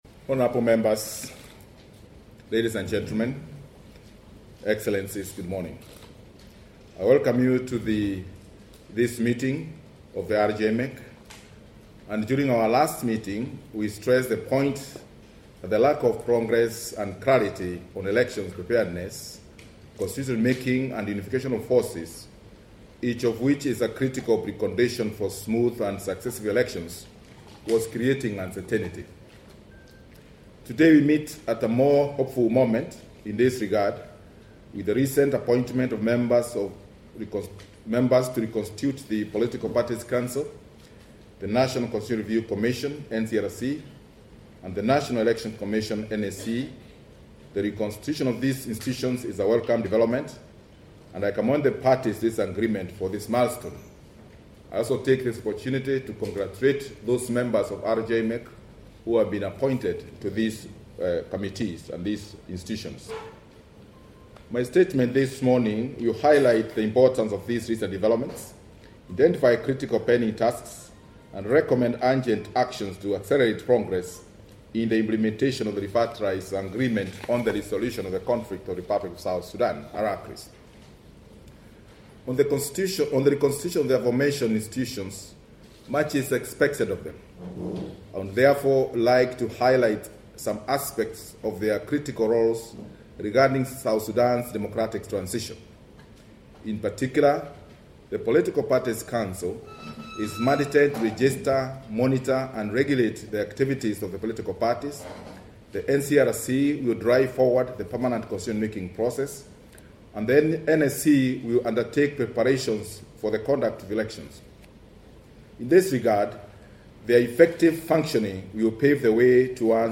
The interim chair of the Reconstituted Joint Monitoring and Evaluation Commission, Charles Tai Gituai's speech at R-JMEC's plenary